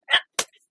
Звуки броненосца: чихание броненосца